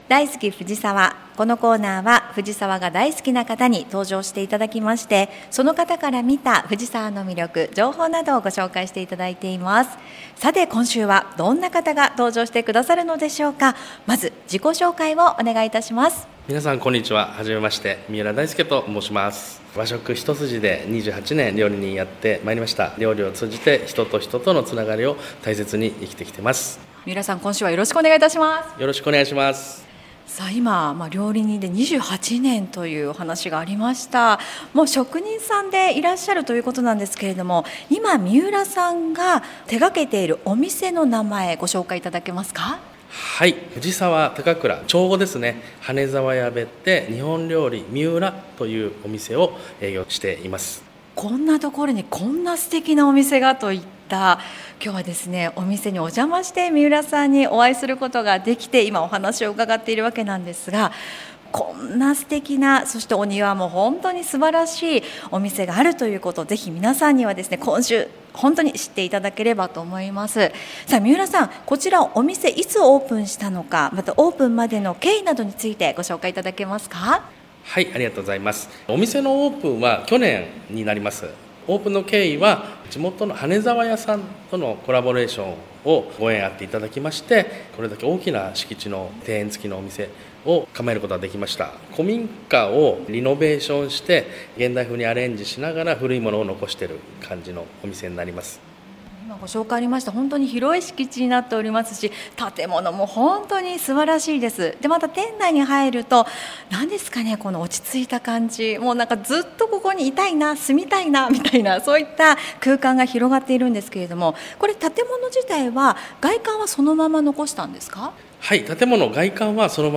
令和5年度に市の広報番組ハミングふじさわで放送された「大好きふじさわ」のアーカイブを音声にてご紹介いたします。